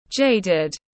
Jaded /ˈdʒeɪdɪd/